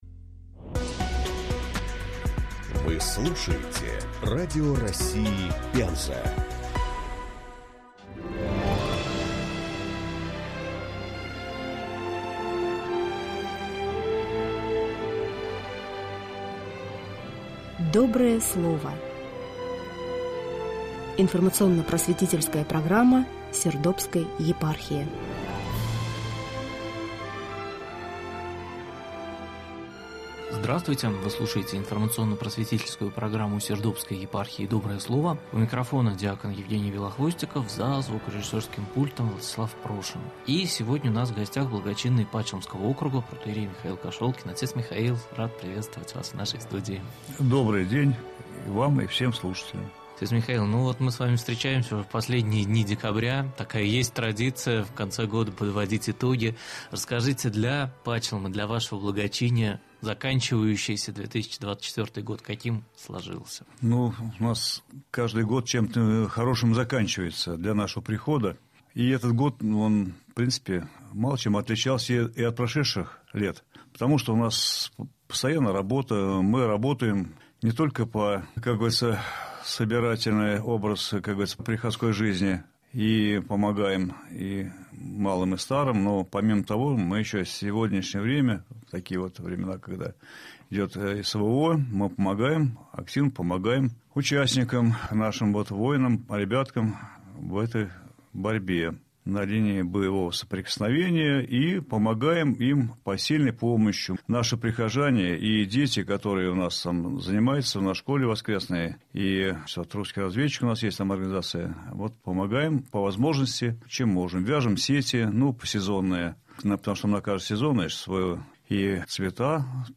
Программа «Доброе слово» выходит в эфир на «Радио России из Пензы» в последнюю пятницу месяца с 18.40 до 19.00, частота в Пензе 94.2 FM (частота вещания в Сердобске — 68,8 МГц, в Пачелме — 66,8, в Наровчате — 70,8, в Белинском — 71,2, в Малой Сердобе — 106,4).